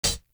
Sun Hat.wav